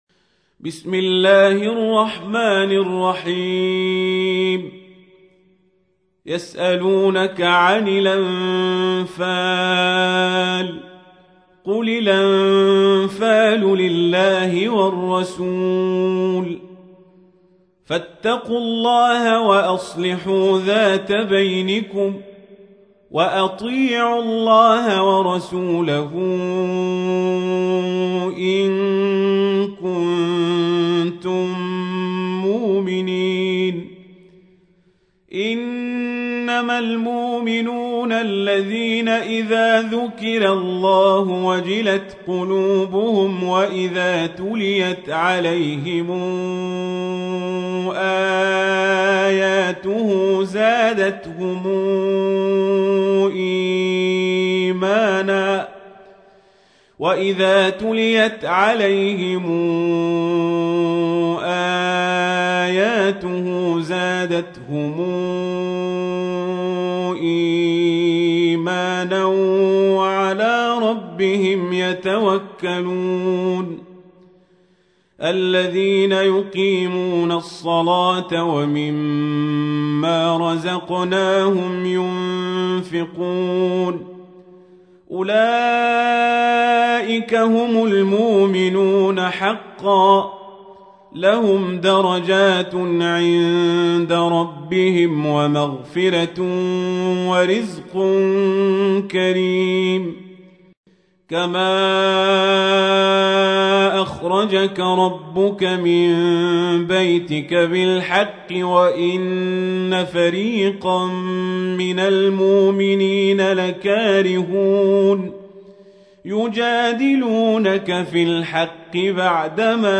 تحميل : 8. سورة الأنفال / القارئ القزابري / القرآن الكريم / موقع يا حسين